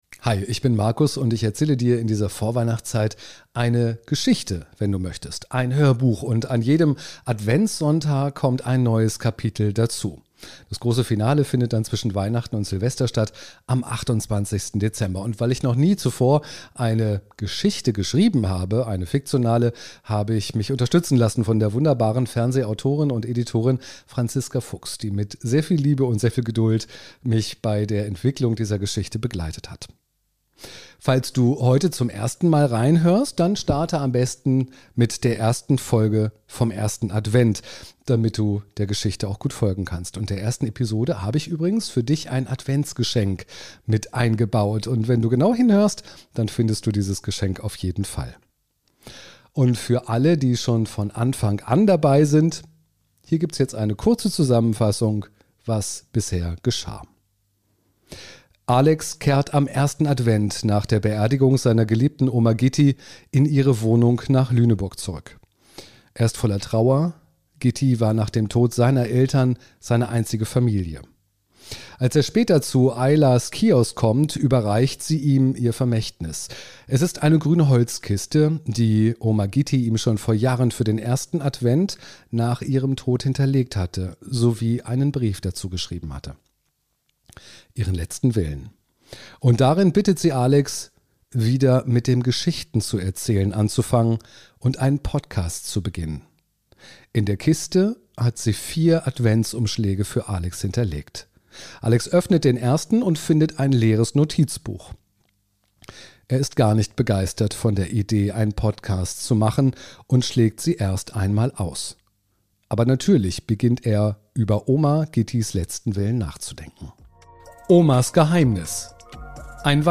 „Omas Geheimnis“ ist mein weihnachtlicher Hörbuch-Mehrteiler: eine Geschichte über Mut, verborgene Wahrheiten und die Frage, warum manche Geheimnisse jahrzehntelang im Dunkeln bleiben.
Warmherzig, atmosphärisch, ein bisschen geheimnisvoll – perfekt für eine Tasse Tee, ein Stück Rotweinkuchen und einen stillen Winterabend.